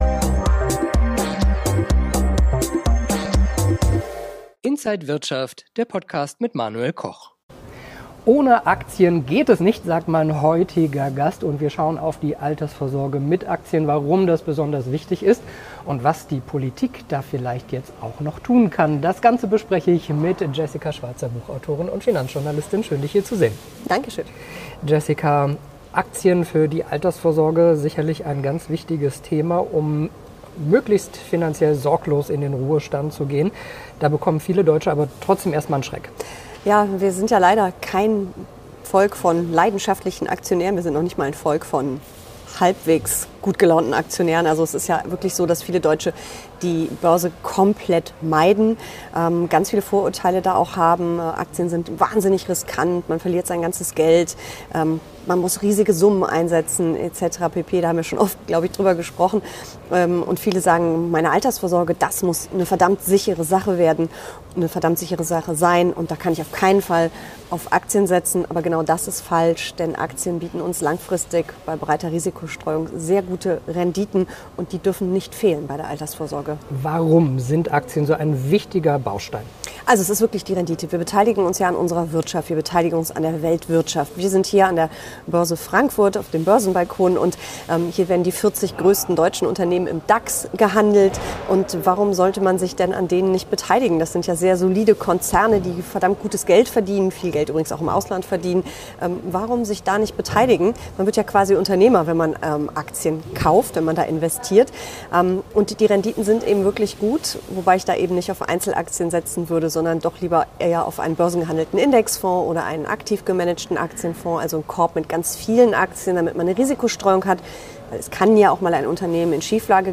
und Finanzjournalistin im Interview von Inside
an der Frankfurter Börse